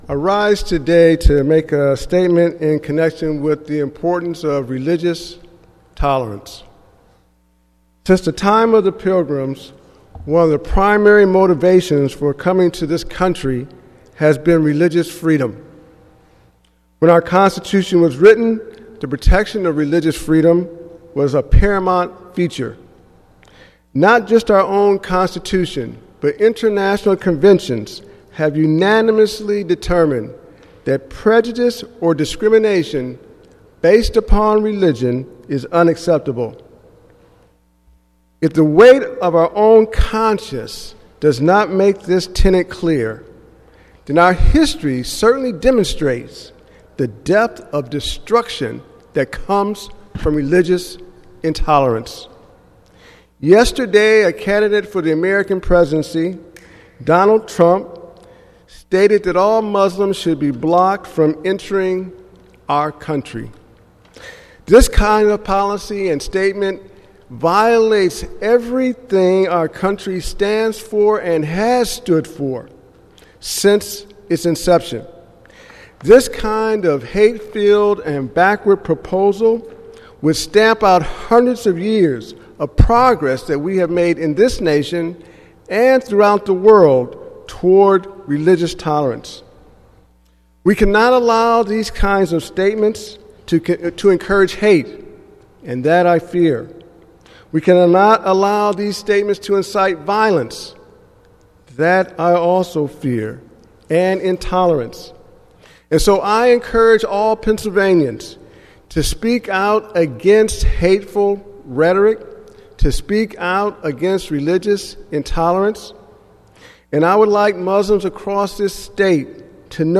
Senator Art Haywood took to the floor of the state Senate to respond to the comments of Republican presidential candidate Donald Trump, who has said he favors banning all Muslims from entering the U.S.